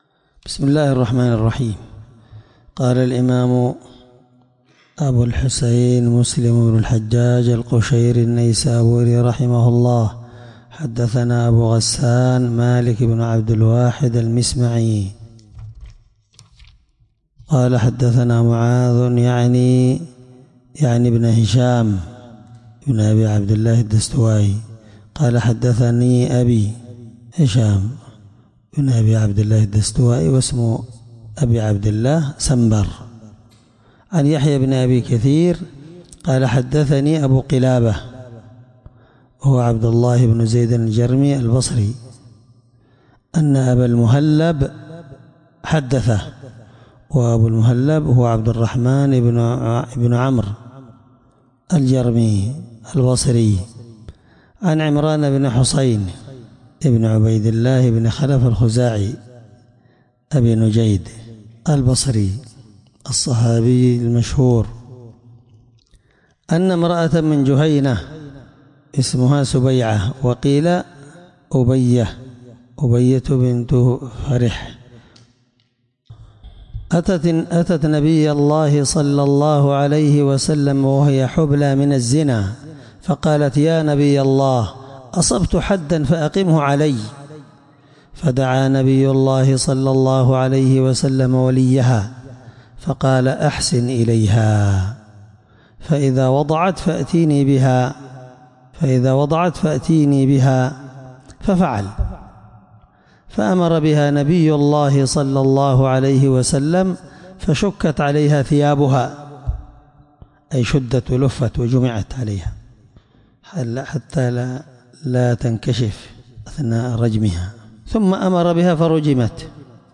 الدرس9من شرح كتاب الحدود حديث رقم(1696) من صحيح مسلم